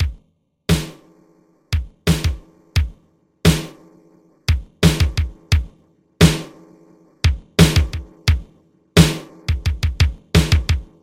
踢腿和小鼓
Tag: 87 bpm Hip Hop Loops Drum Loops 950.47 KB wav Key : Unknown